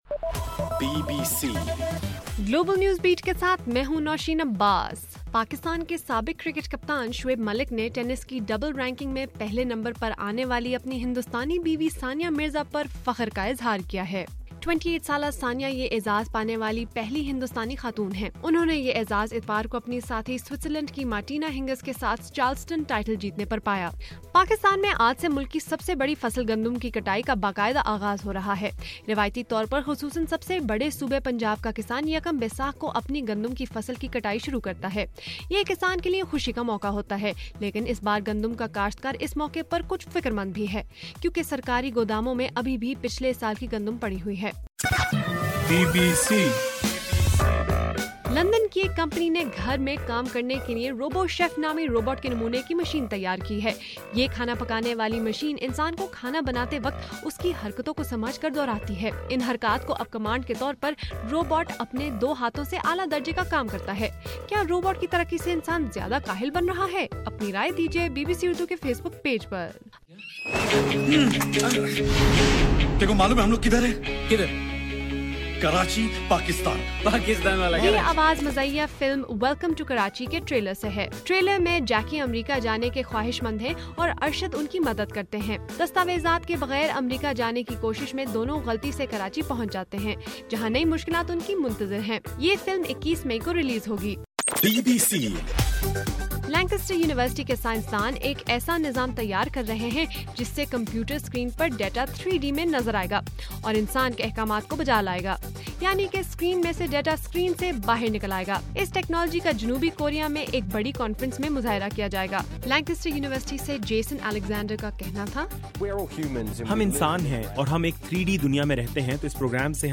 اپریل 15: صبح 1 بجے کا گلوبل نیوز بیٹ بُلیٹن